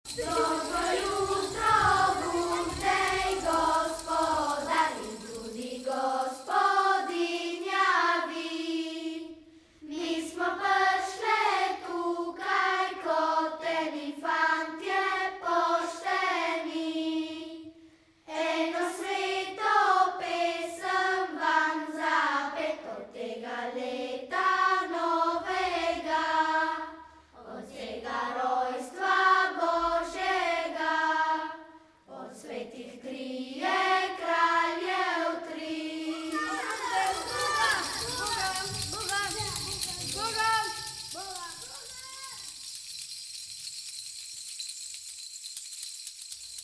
BOŽIČNE IN NOVOLETNE KOLEDNICE S TRŽAŠKEGA